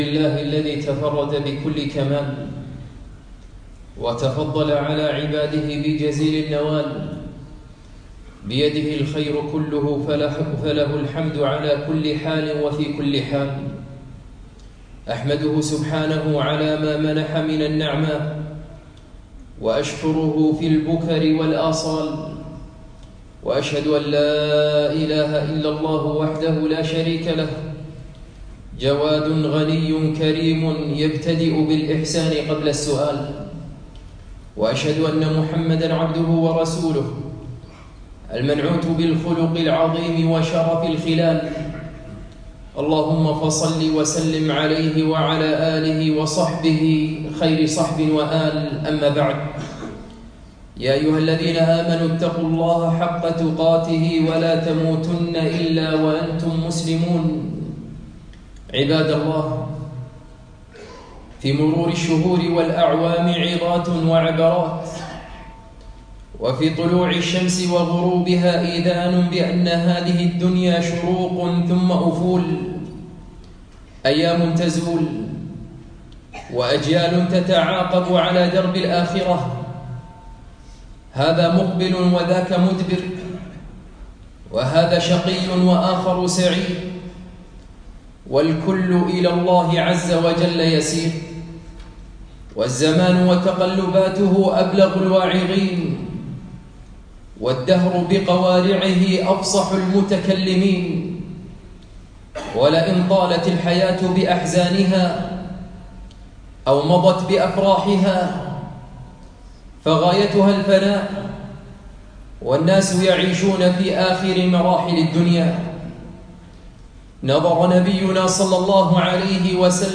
يوم الجمعة 13 شوال 1438 الموافق 7 7 2017 في مسجد العلاء بن عقبة الفردوس